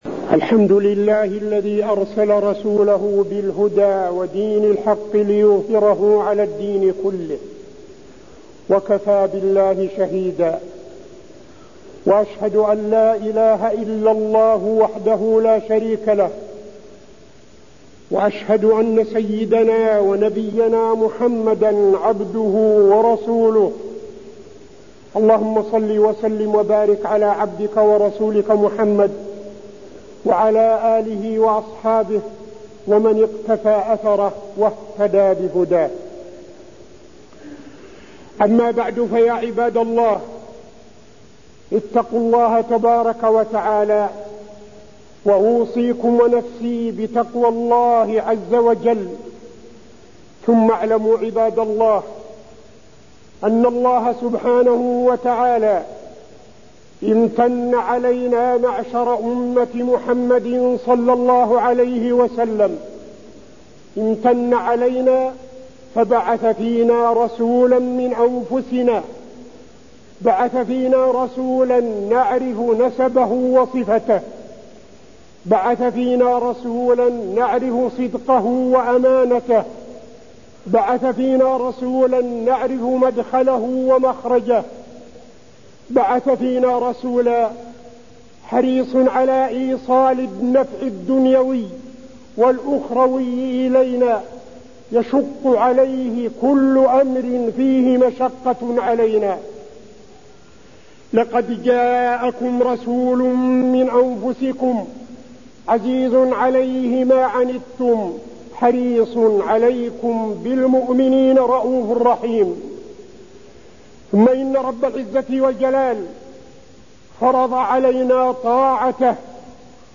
تاريخ النشر ١١ رجب ١٤٠٦ هـ المكان: المسجد النبوي الشيخ: فضيلة الشيخ عبدالعزيز بن صالح فضيلة الشيخ عبدالعزيز بن صالح الحث على طاعة الله ورسوله